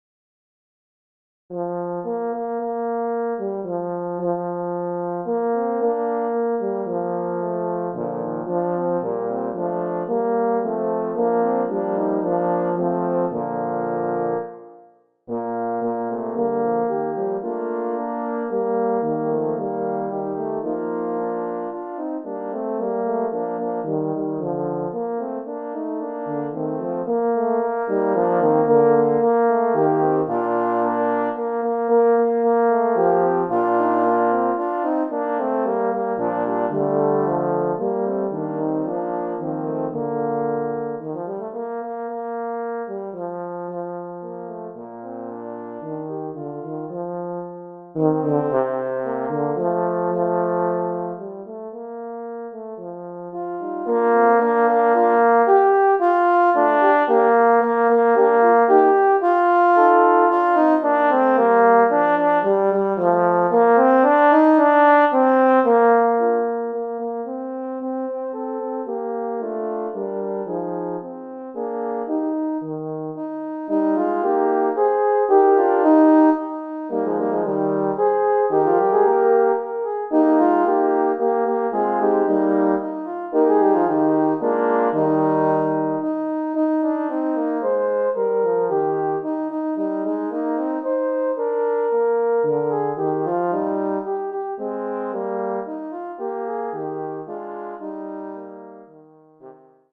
Composer: English Carol
Voicing: Horn Quartet